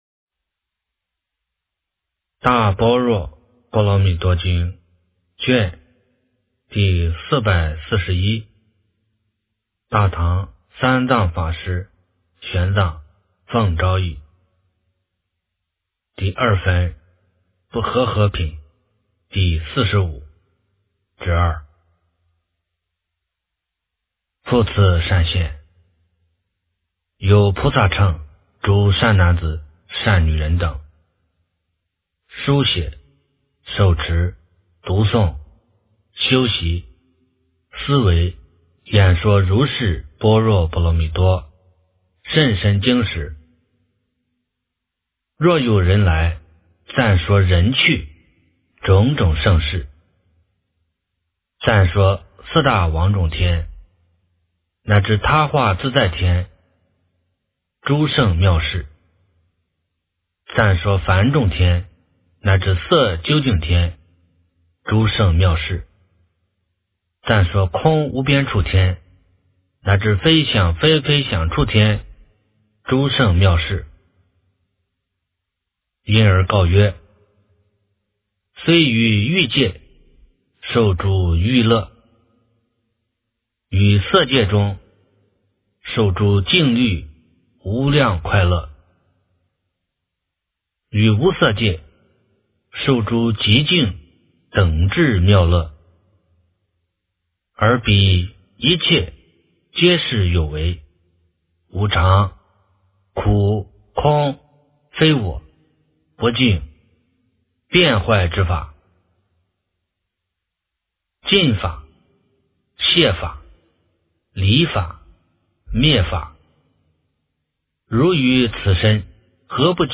大般若波罗蜜多经第441卷 - 诵经 - 云佛论坛